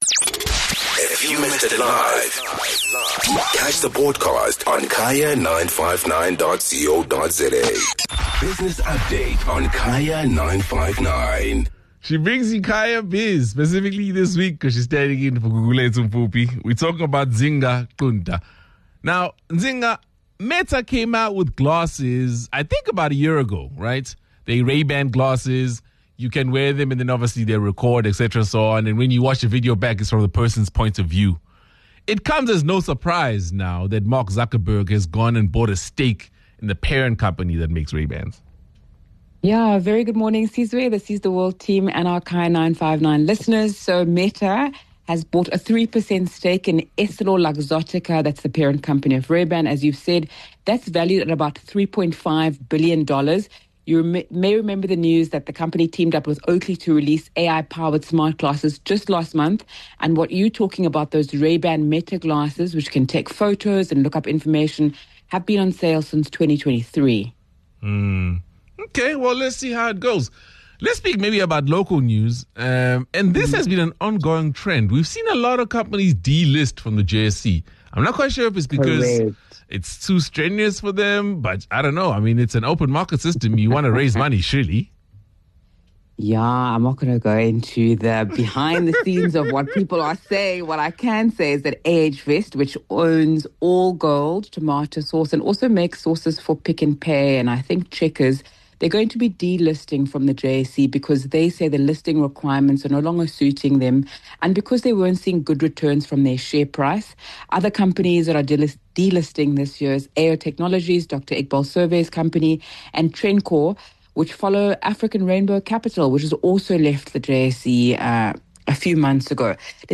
9 Jul Business Update: Meta buys stake in Ray Ban & companies leaving the JSE.